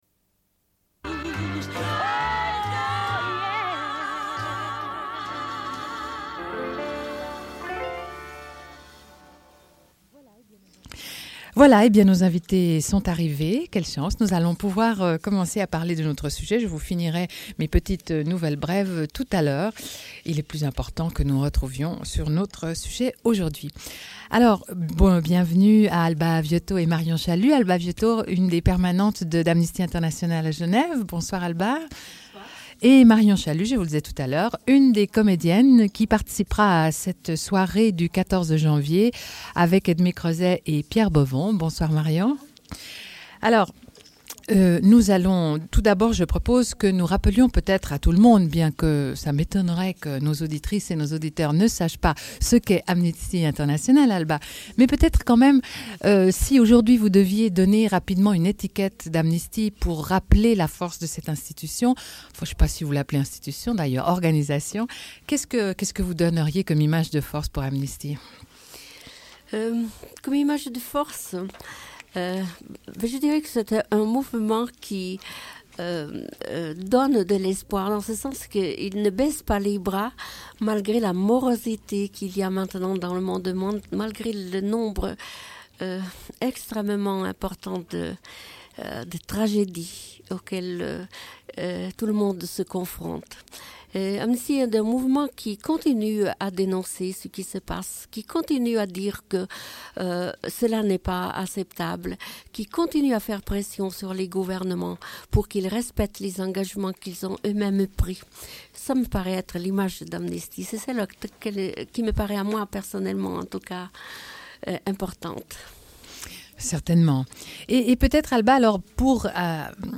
Une cassette audio, face B28:58